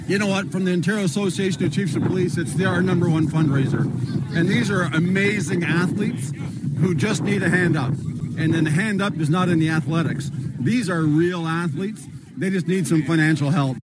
Cobourg Police Chief Paul VandeGraaf, who took the plunge twice, says it was all for a great cause.